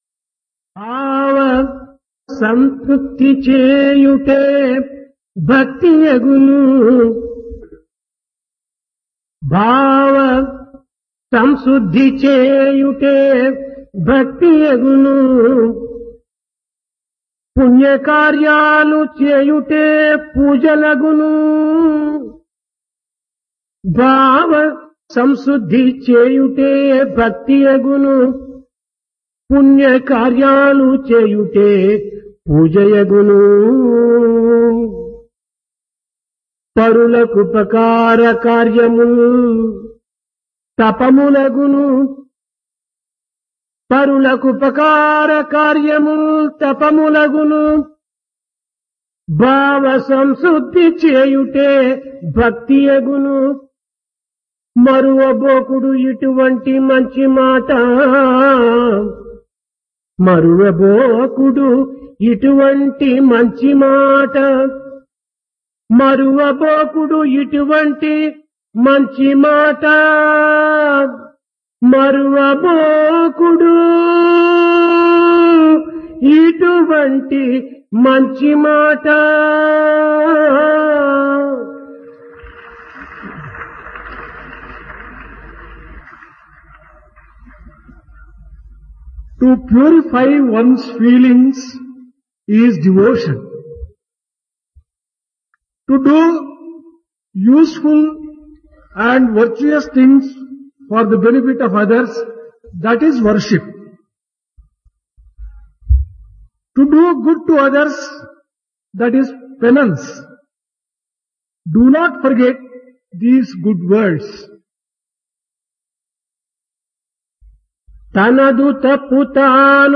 Dasara - Divine Discourse | Sri Sathya Sai Speaks
Place Prasanthi Nilayam Occasion Dasara